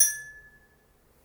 Ding foley practice sound effect free sound royalty free Sound Effects